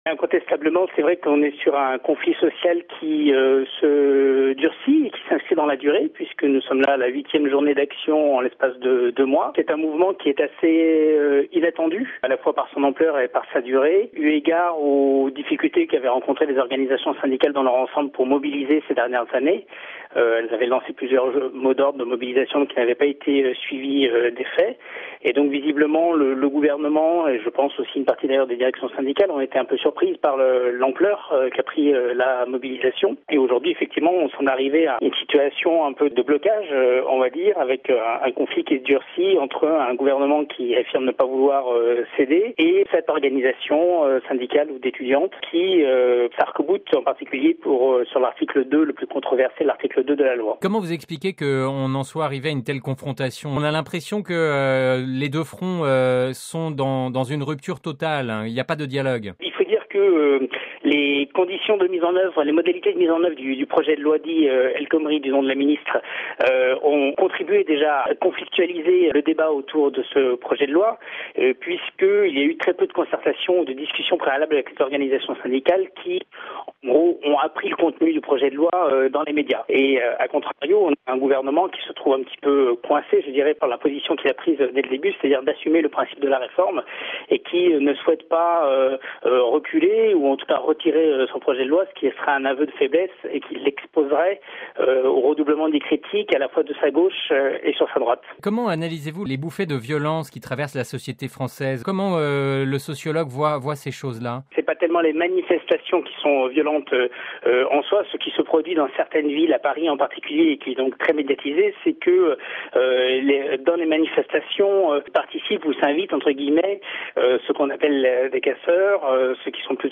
(RV) Entretien - Le climat social est toujours très lourd en France, notamment depuis le passage en force du gouvernement sur la loi travail.